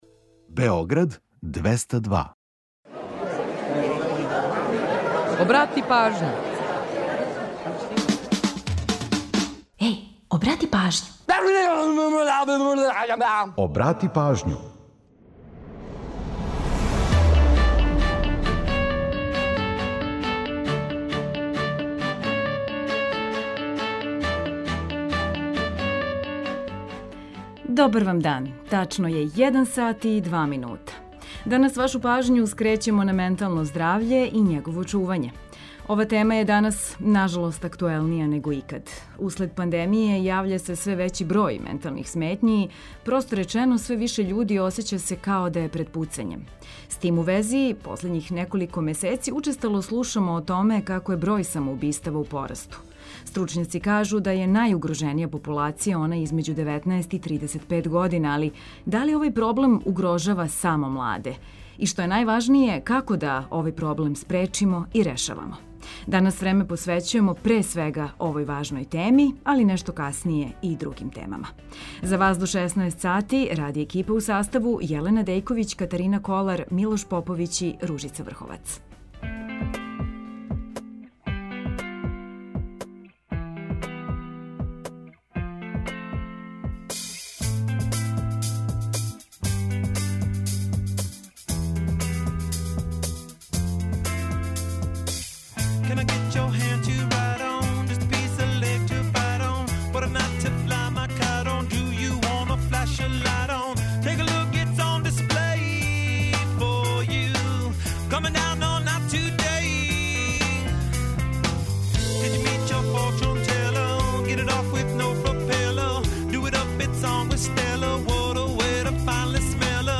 Доносимо новости са светских топ листа, као и пола сата само домаће музике и музике из региона.